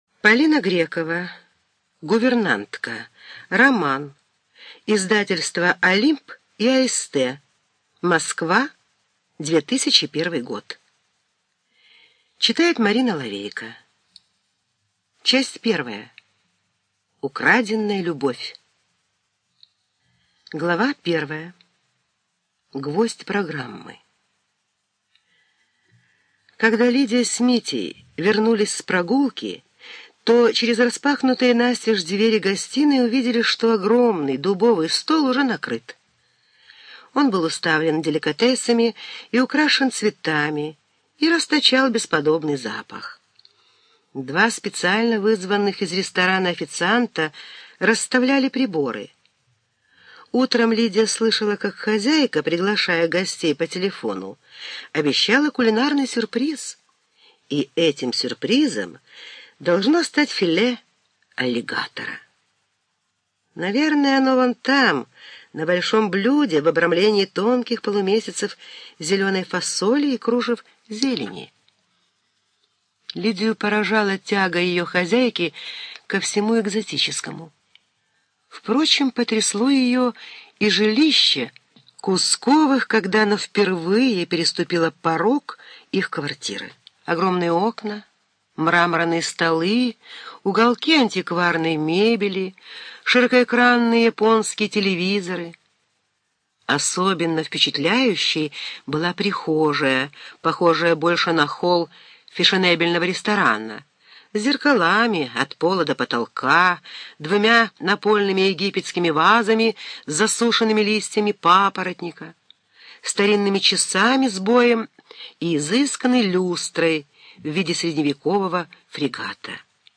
ЖанрЛюбовная проза